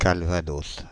Ääntäminen
Synonyymit calva Ääntäminen France (Paris): IPA: /kal.va.dos/ Haettu sana löytyi näillä lähdekielillä: ranska Käännöksiä ei löytynyt valitulle kohdekielelle.